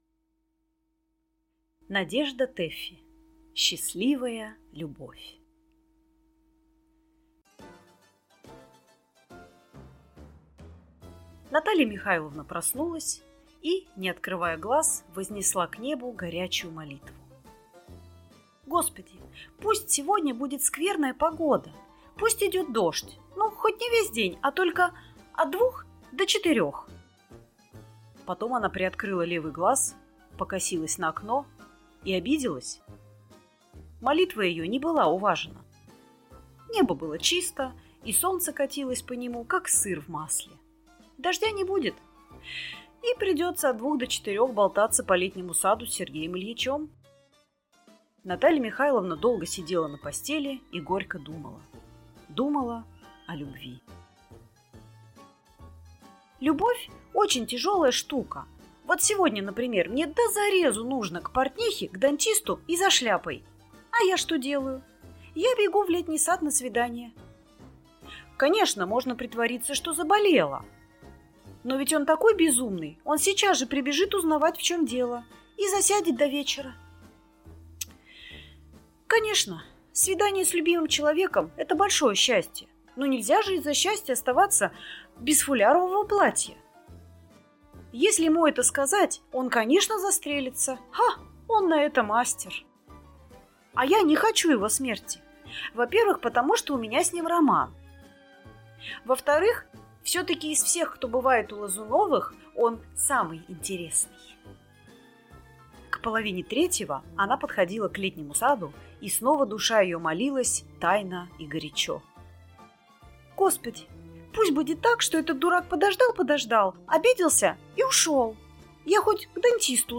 Аудиокнига Счастливая любовь